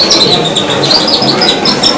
バードコール
100回ほどねじ込んだりゆるめたりすると、 回すたびに「キュッキュッ」っと鳴るようになって、 うまく鳴らせば鳥が寄ってくるそうです。